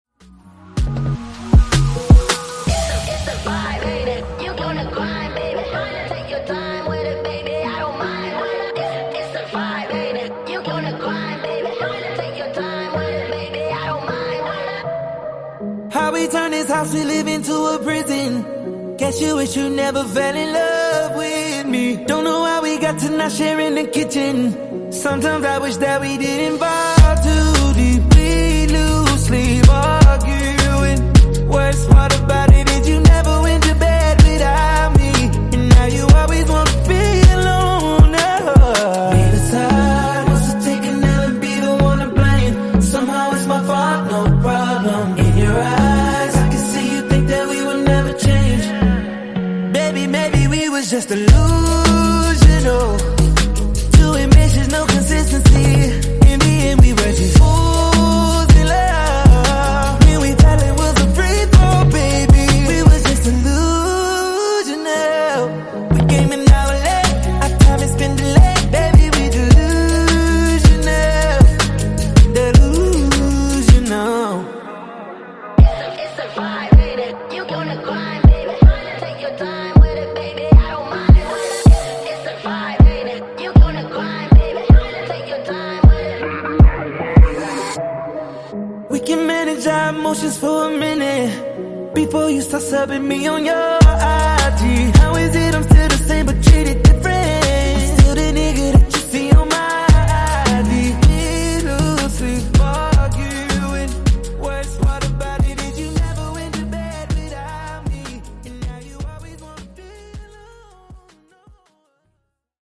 Genre: RE-DRUM
Clean BPM: 97 Time